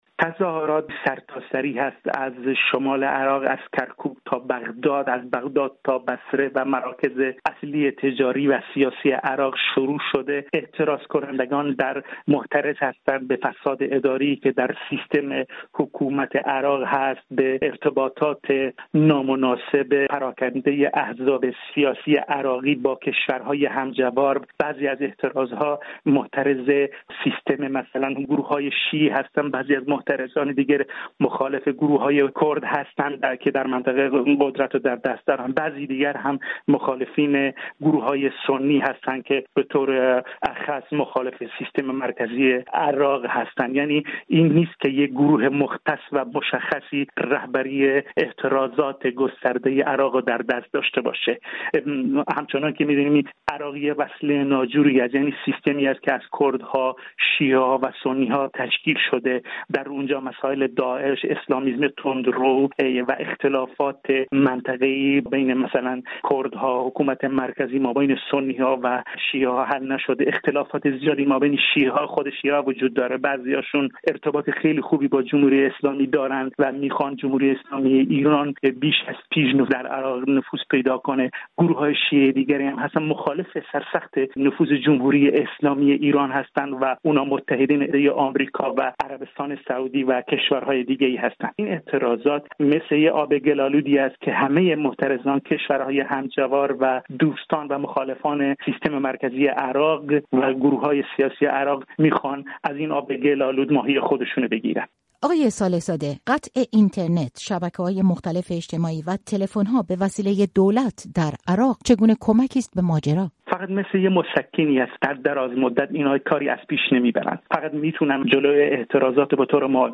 گفت‌وگو
پژوهشگر ژئوپلیتیک و کارشناس خاورمیانه، درباره اعتراض‌های عراق